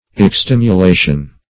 Search Result for " extimulation" : The Collaborative International Dictionary of English v.0.48: Extimulation \Ex*tim`u*la"tion\, n. Stimulation.